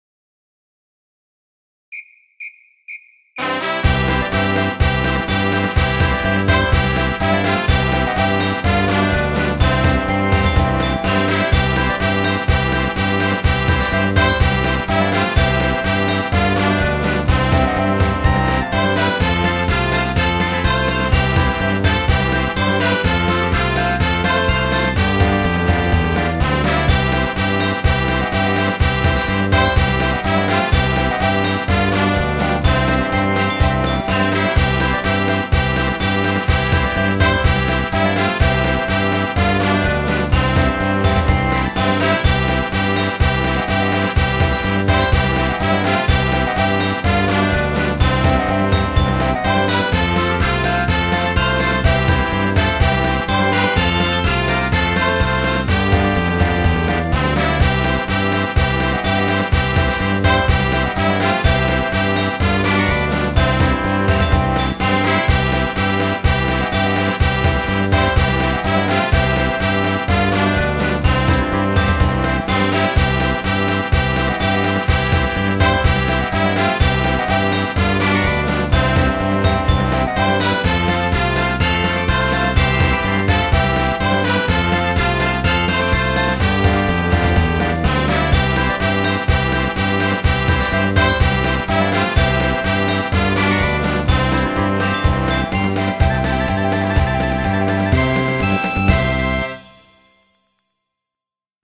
Midi File, Lyrics and Information to The Marine's Hymn